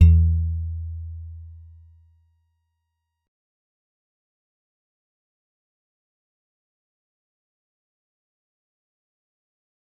G_Musicbox-E2-mf.wav